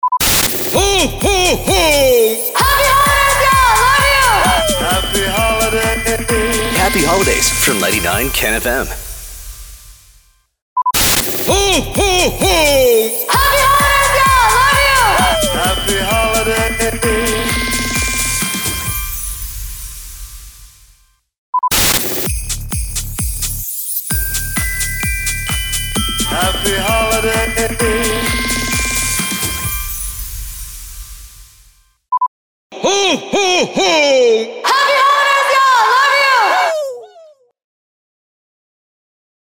145 – SWEEPER – CHRISTMAS – HAPPY HOLIDAYS
145-SWEEPER-CHRISTMAS-HAPPY-HOLIDAYS.mp3